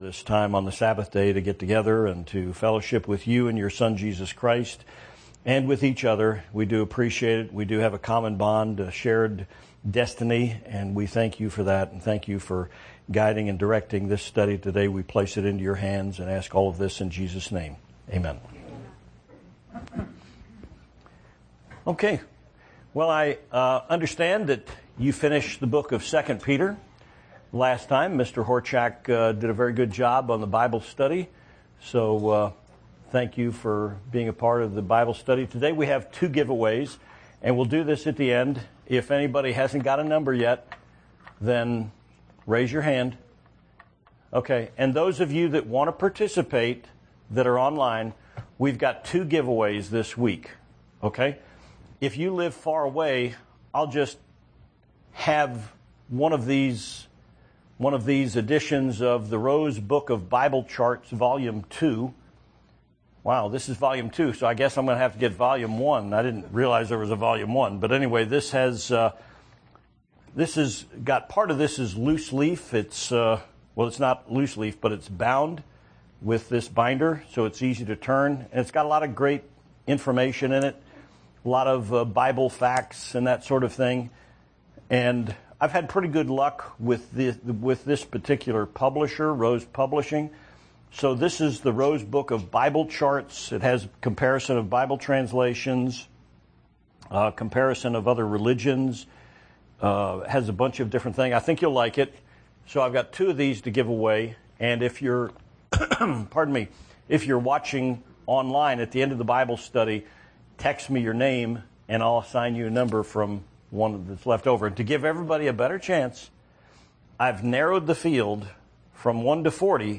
Given in El Paso, TX Tucson, AZ